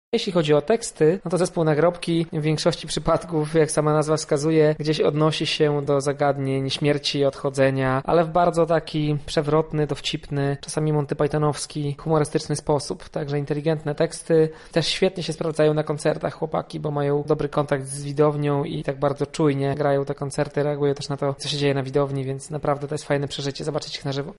Grobową atmosferę wprowadzi koncert trójmiejskiego duetu.
połączenie czarnego humoru z alternatywnym brzmieniem